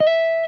SLIDESOLO6.wav